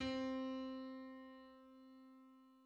unison 0 0
Middle_C.mid.mp3